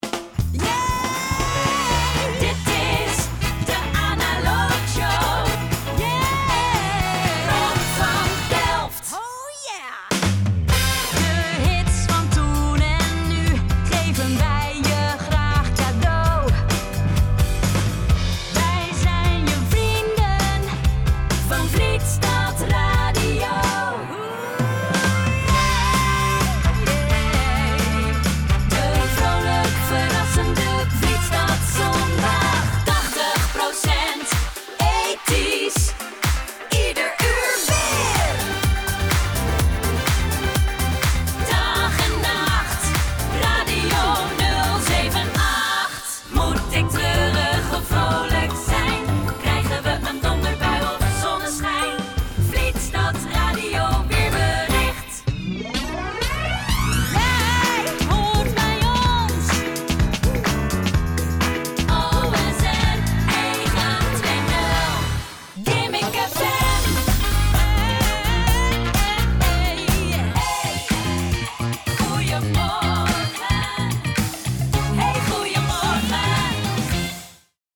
Radio Imaging
My voice sounds young, fresh and enthusiastic, but reliable.
Mic: Sennheiser MKH416